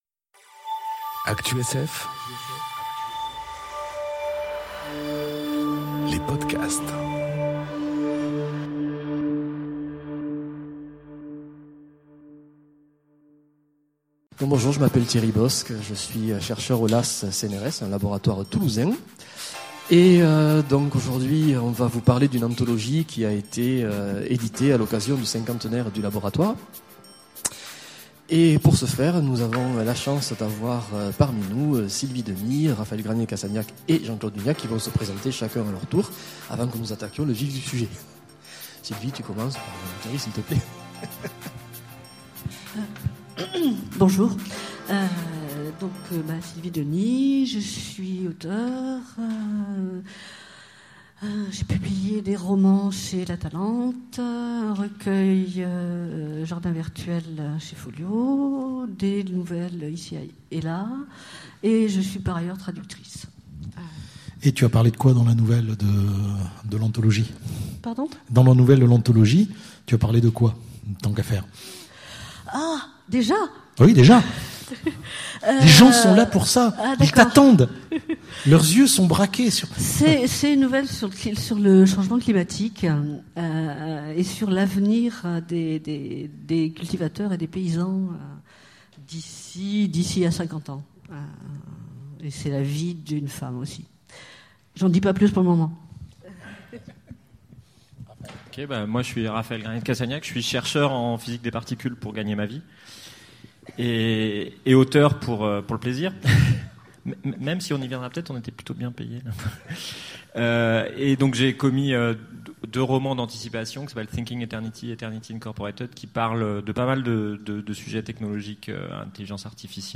Conférence Anthologie du LAAS enregistrée aux Utopiales 2018